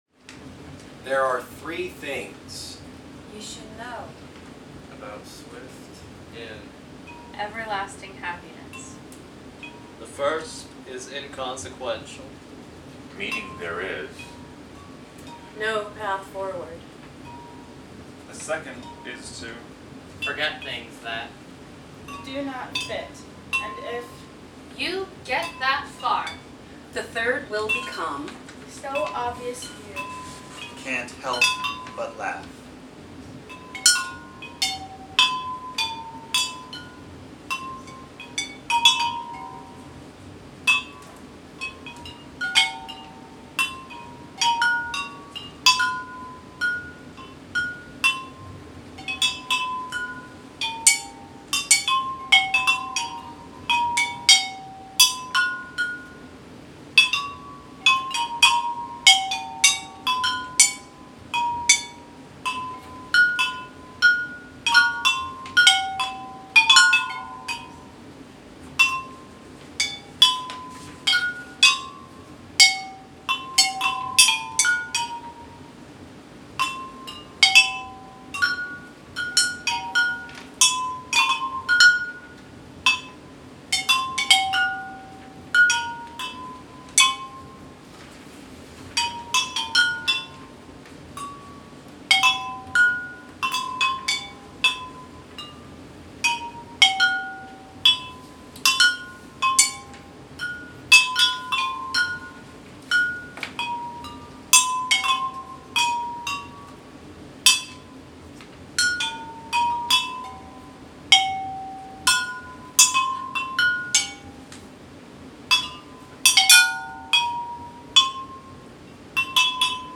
for 14 light fixture performers
Recorded at Fusion Academy Newton, MA